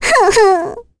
Requina-vox-Sad_kr.wav